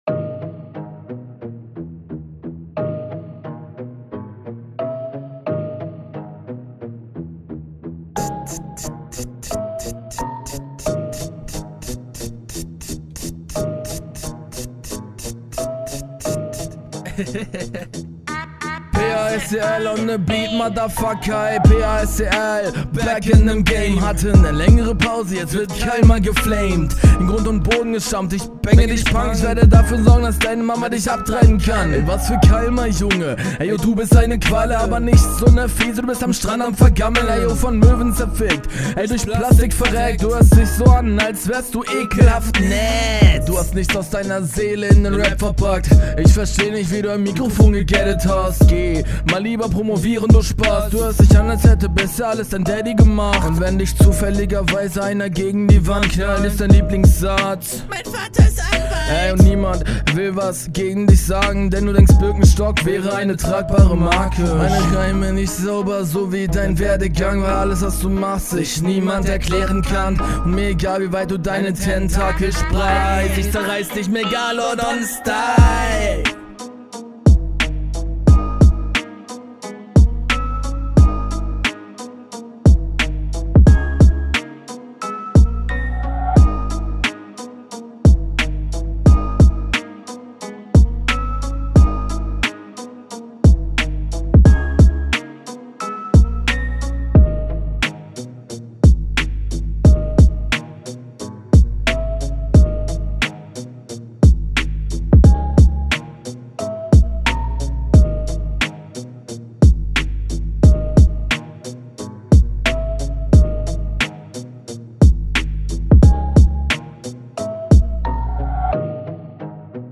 Der Stimmeinsatz ist mir viel zu gedrückt, die Delivery gefällt mir auch nicht, alles viel …